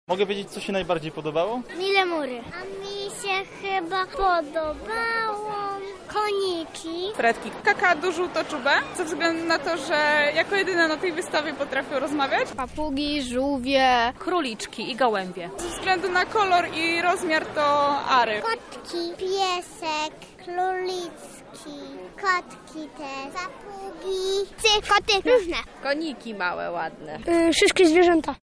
Lubelska wystawa zoologiczna „ZooPark”